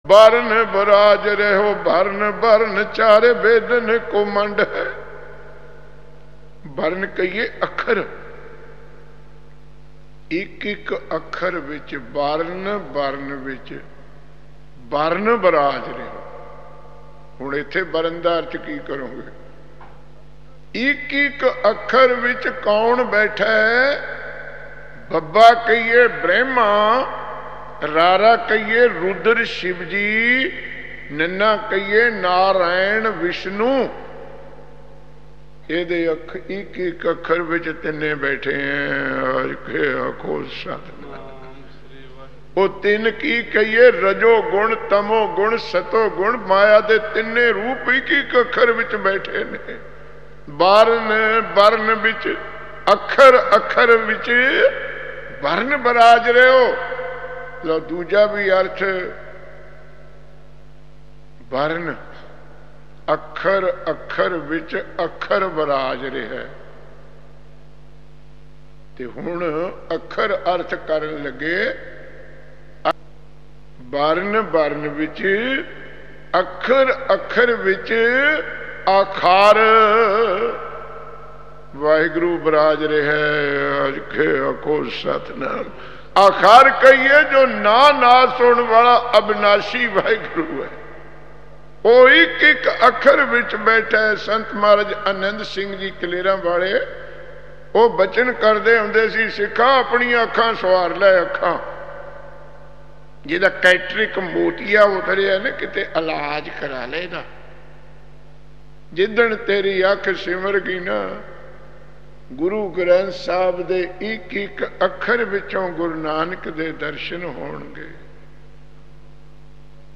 Kirtan with katha
Genre: Gurmat Vichar